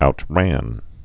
(out-răn)